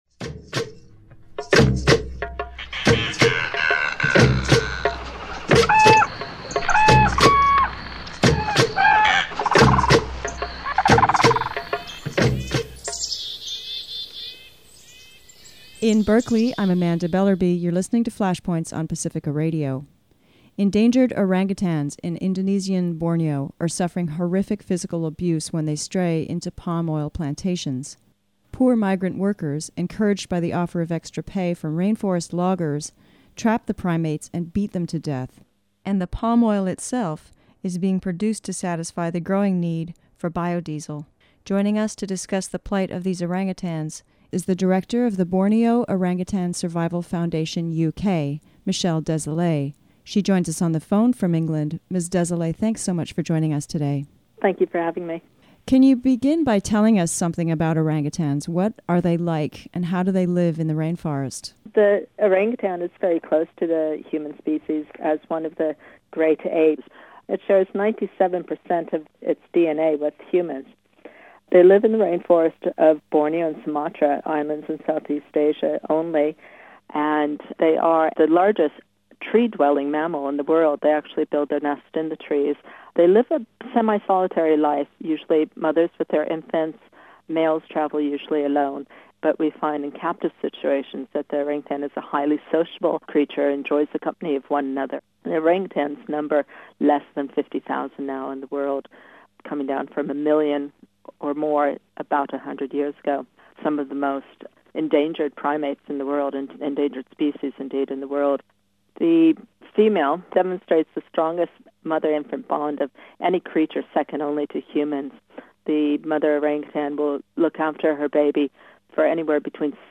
EARTH MATTERS radio - environmental news for KPFA's investigative news show, "Flashpoints."